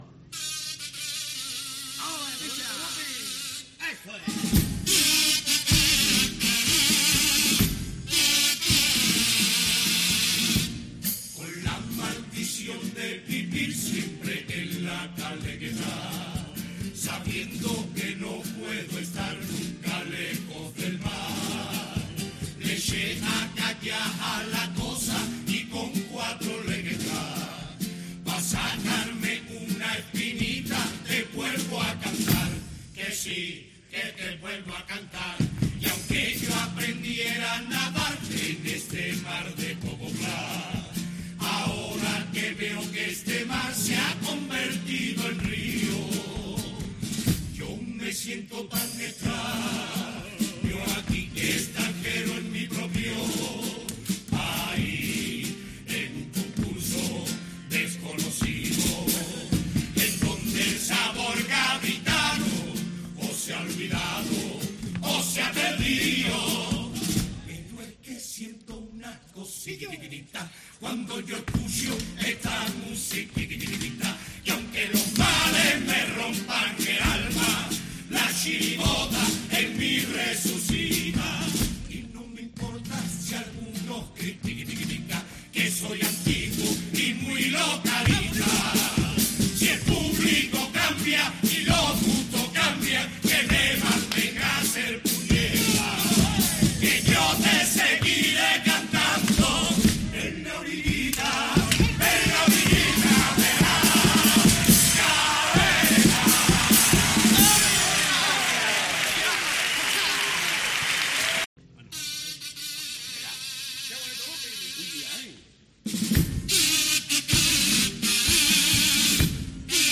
Los dos mejores pasodobles de La maldición de la lapa negra
Carnaval
Los dos pasodobles de la final obtuvieron la mejor puntuación del jurado